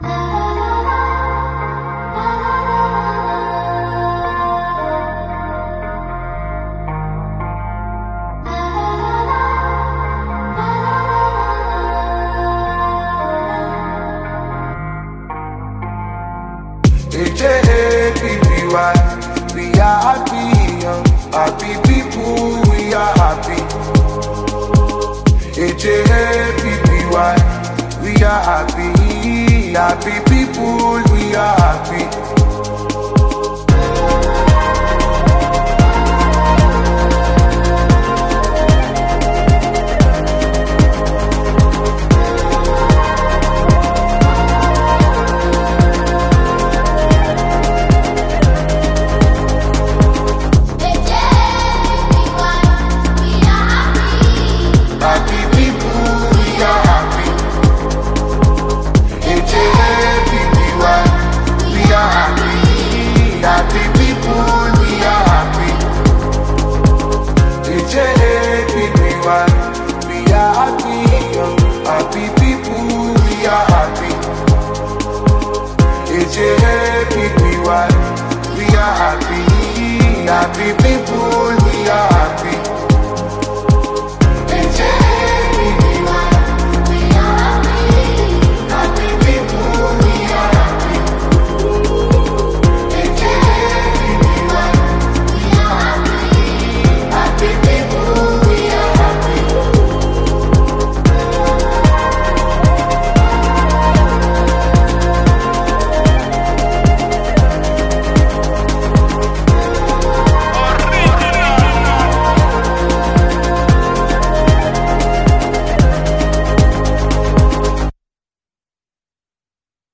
the highly acclaimed Nigerian Afrobeat sensation
With its mid-tempo beat and smooth melodies